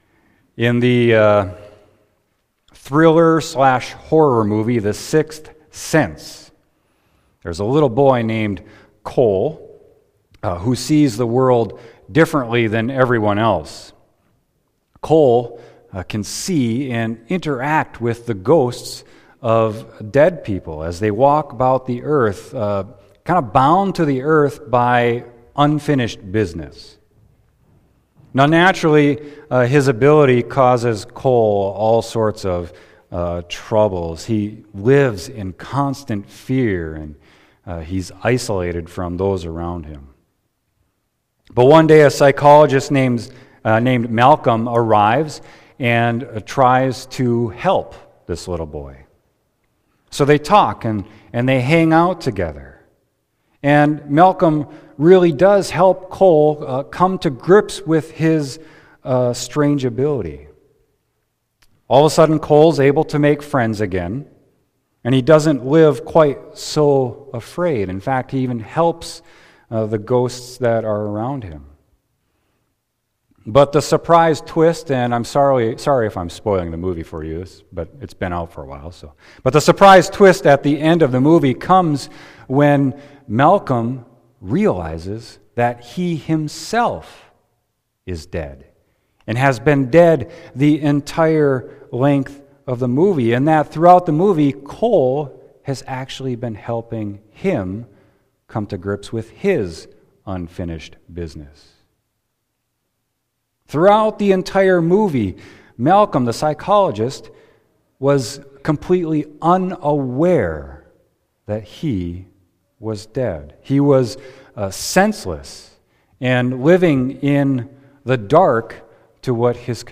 Sermon: 1 Thessalonians 5.1-11